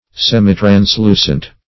Search Result for " semitranslucent" : The Collaborative International Dictionary of English v.0.48: Semitranslucent \Sem`i*trans*lu"cent\, a. Slightly clear; transmitting light in a slight degree.